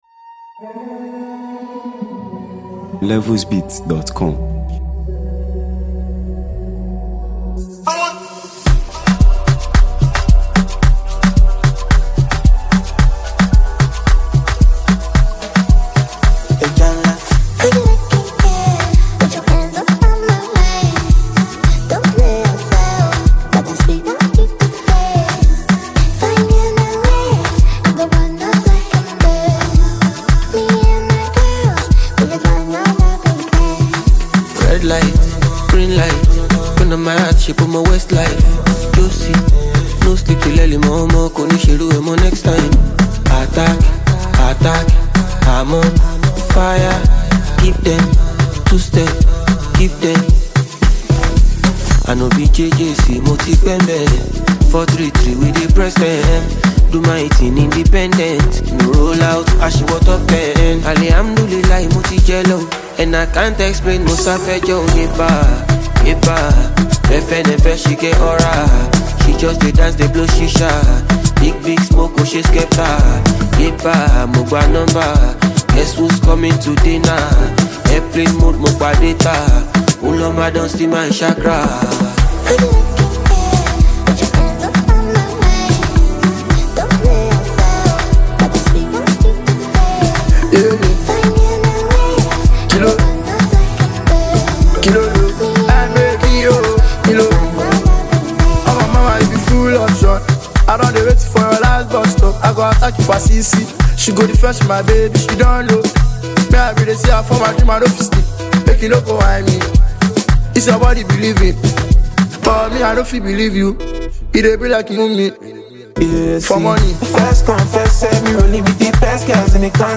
Nigeria Music 2025 2:53